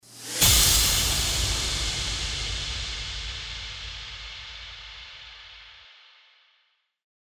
FX-650-IMPACT-CRASH
FX-650-IMPACT-CRASH.mp3